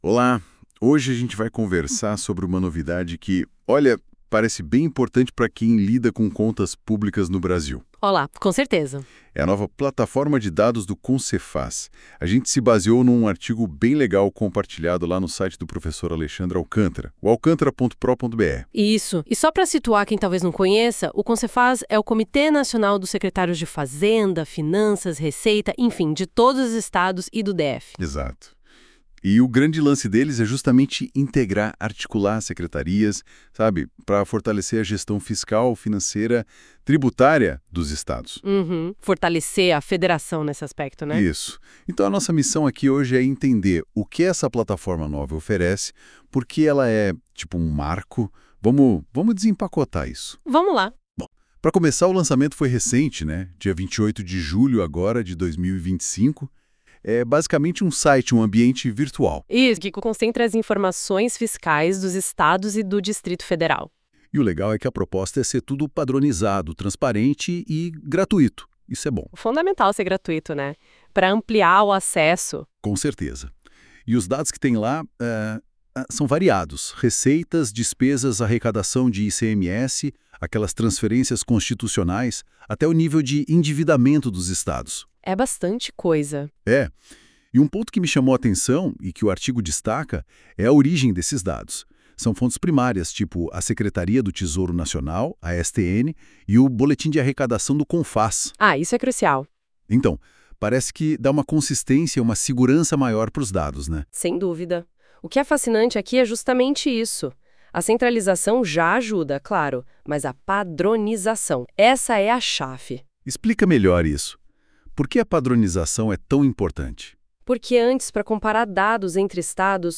Confira o podcast preparado por IA com base no artigo